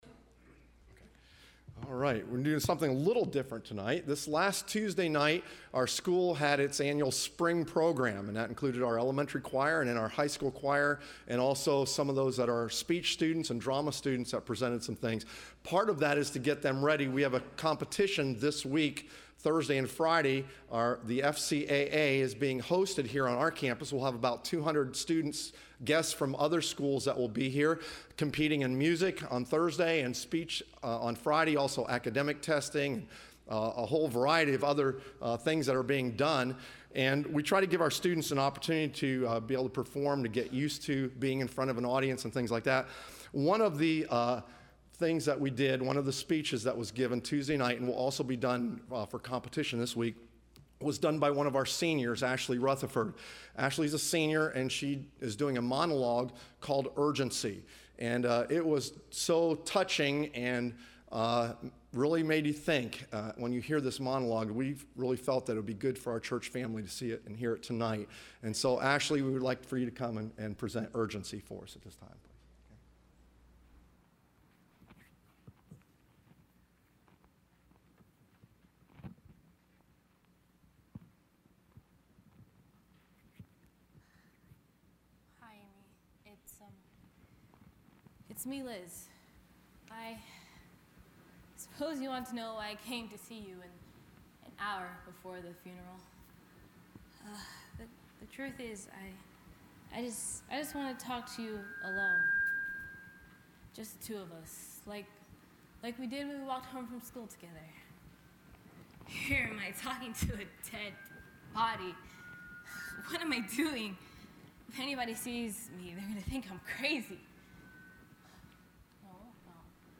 Soulwinning Marathon Testimonies – Landmark Baptist Church
Service Type: Sunday Evening